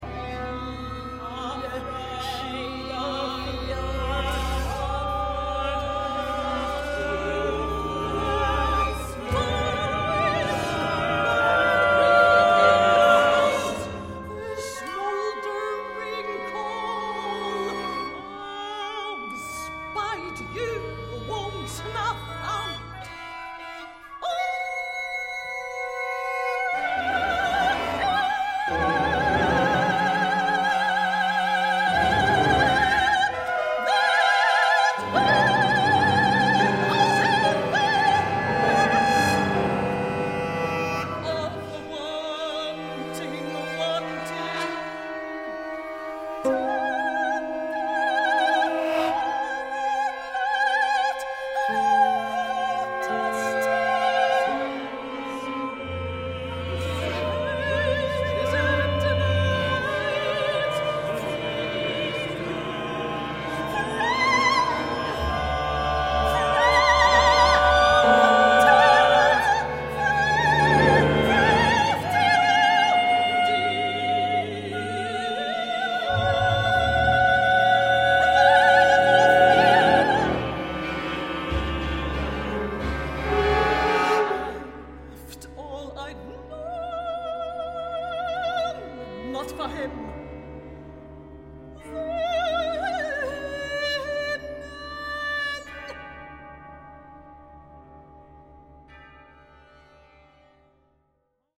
one act opera
Westdeutscher Rundfunk, Cologne, Germany